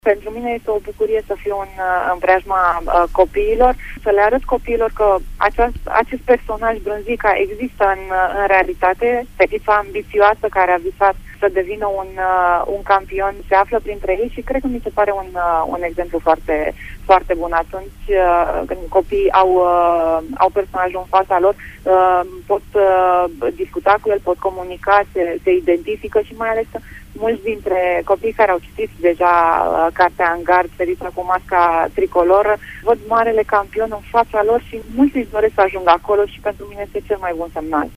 Pentru Radio Tg.Mureș, Ana Maria Brânză: